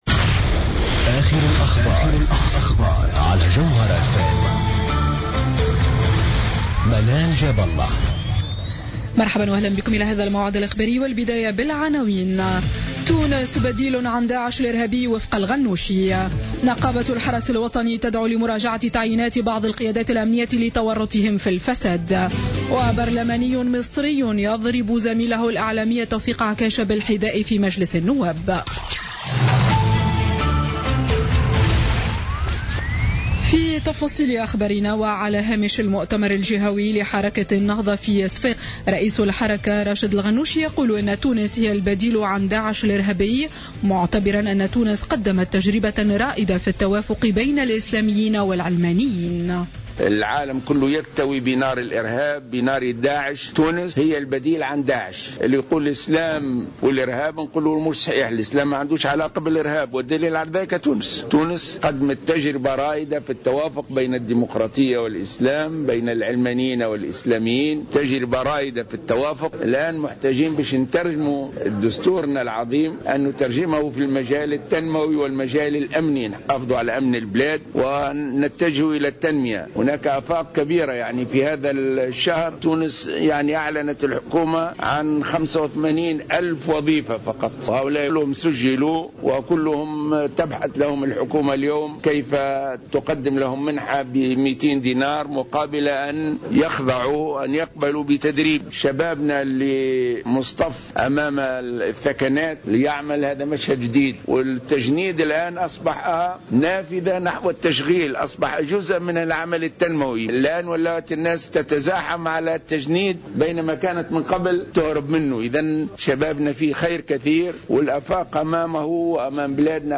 Journal Info 19H00 du Dimanche 28 Février 2016